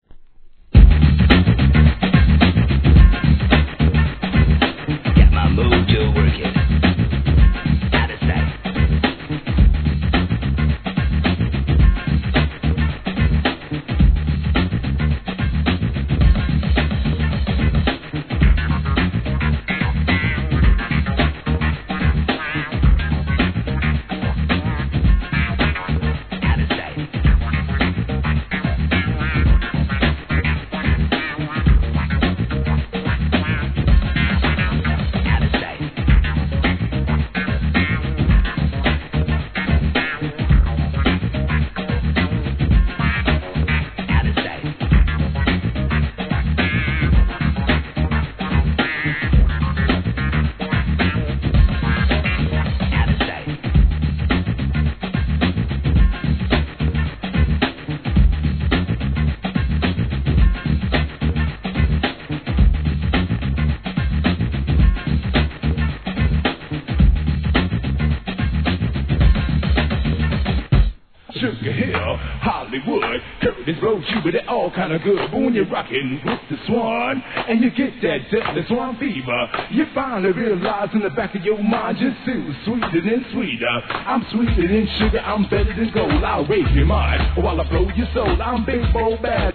HIP HOP/R&B
ブレイク・ビーツ No. タイトル アーティスト 試聴 1.